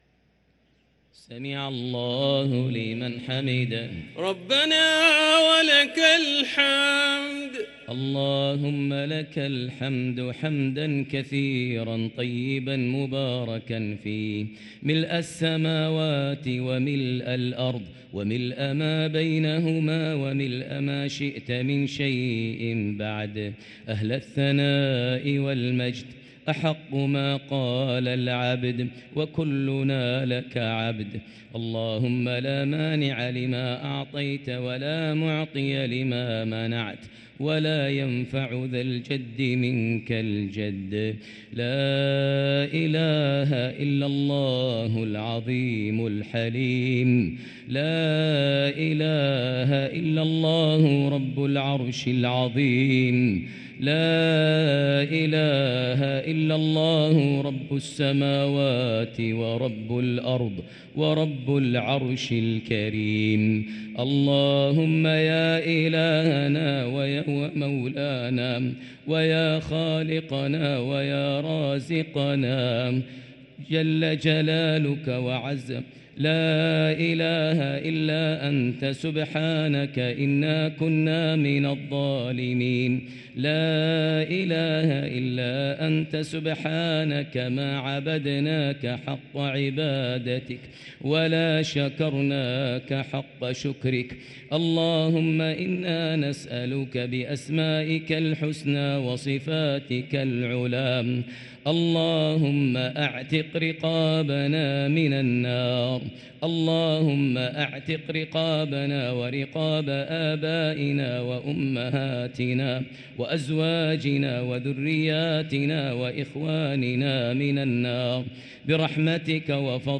| Dua for the night of 24 Ramadan 1444H > Taraweh 1444H > Taraweeh - Maher Almuaiqly Recitations